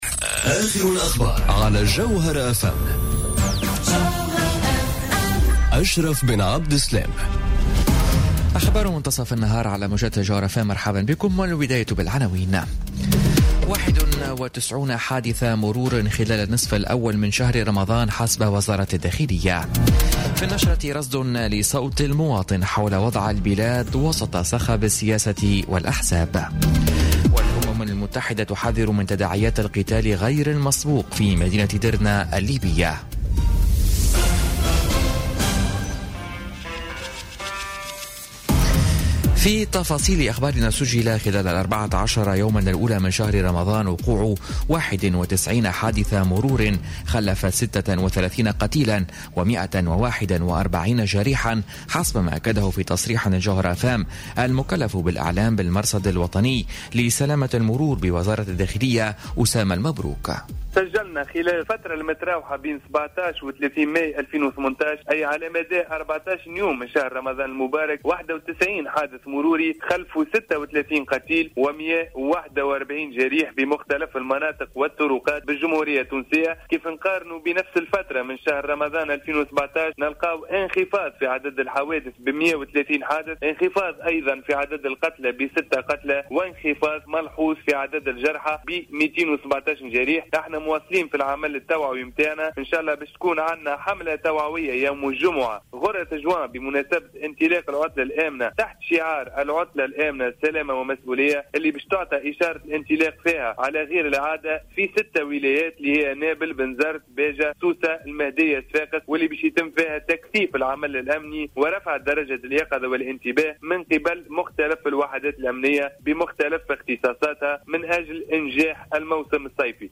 نشرة أخبار منتصف النهار ليوم الخميس 31 ماي 2018